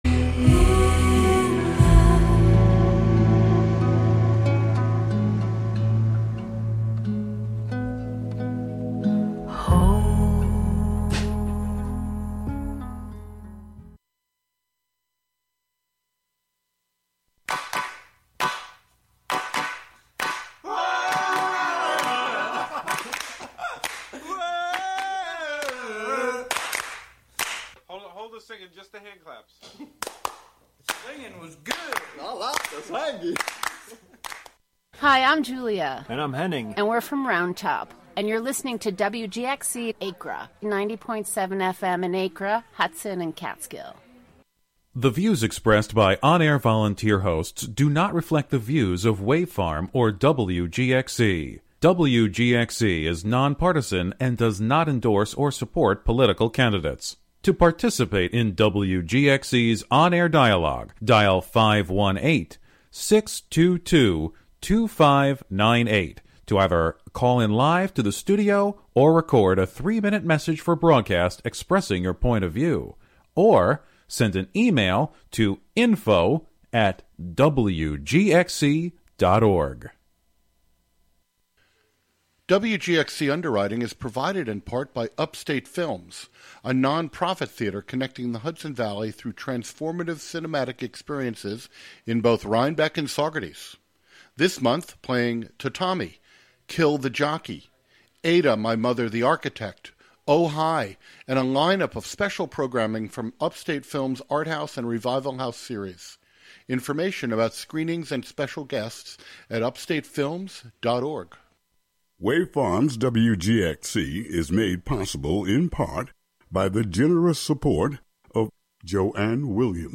I like to start them out at 125 bpm and just keep building.